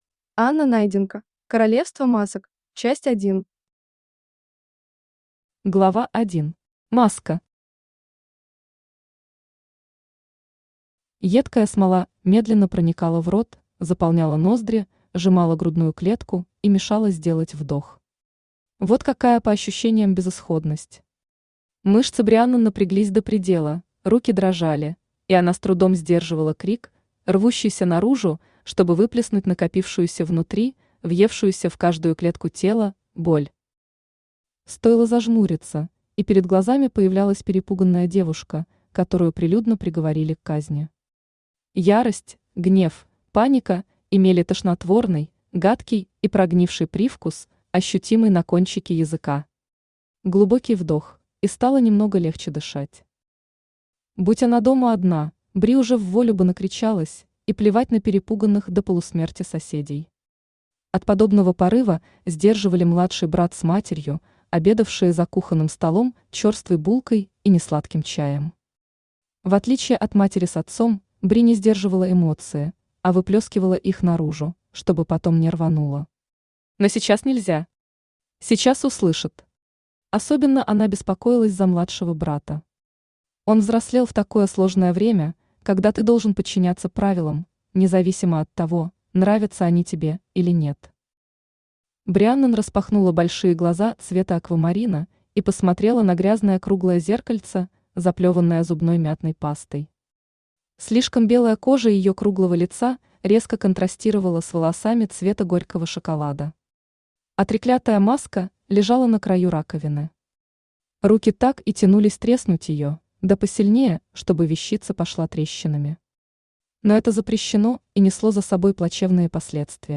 Аудиокнига Королевство Масок. Часть 1 | Библиотека аудиокниг
Часть 1 Автор Анна Найденко Читает аудиокнигу Авточтец ЛитРес.